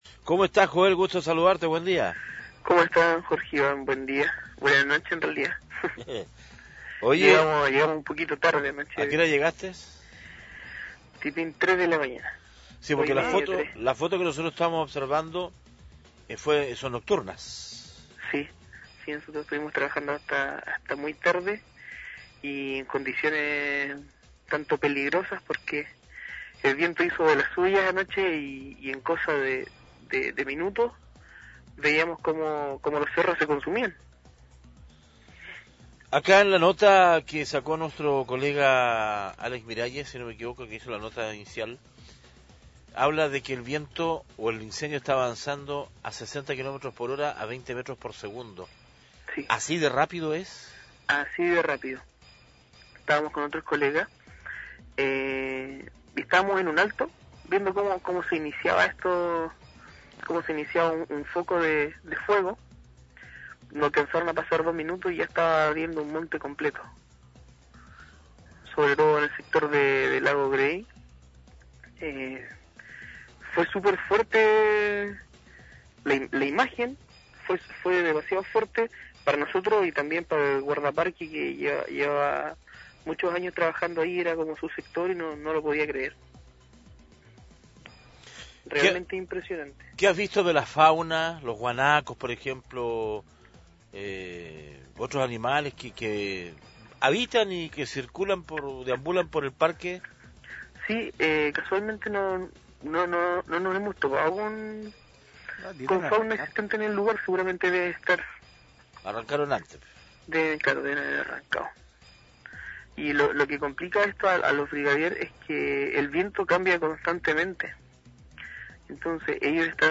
Entrevistas de Pingüino Radio